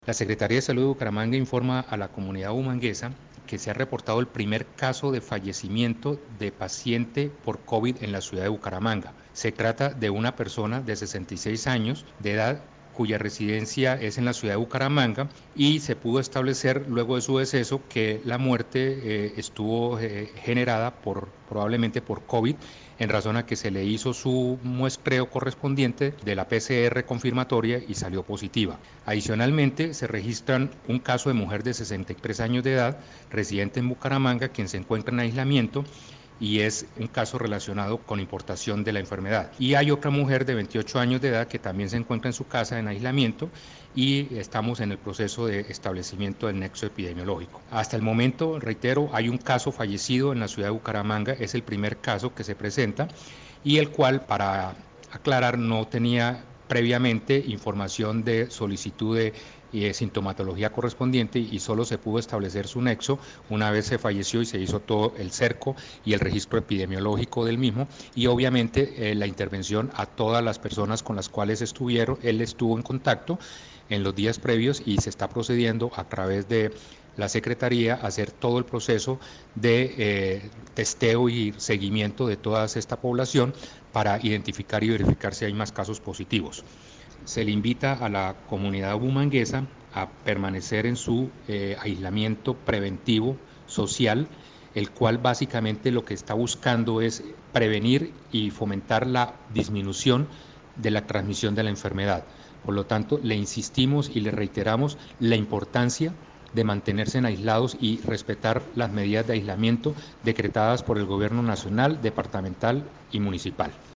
Nelson Ballesteros – Secretario de Salud de Bucaramanga
Nelson-Ballesteros-Secretario-de-Salud-de-Bucaramanga.mp3